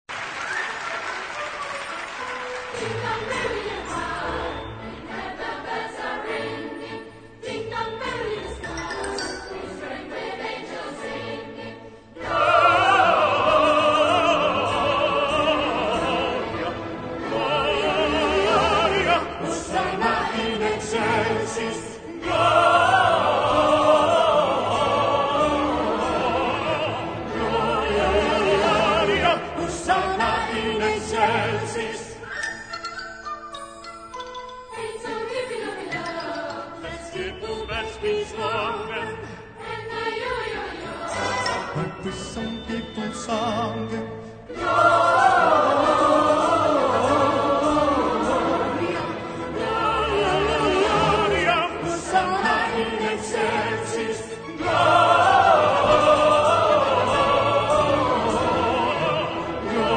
key: A-major